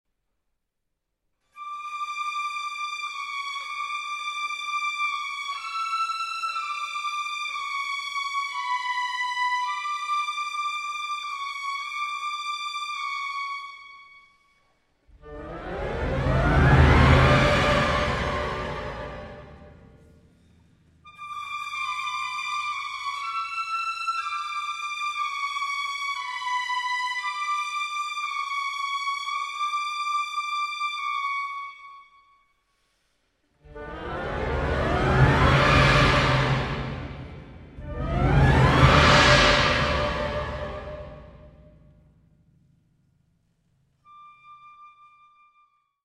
First Recordings, Live